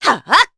Hilda-Vox_Attack2_jp.wav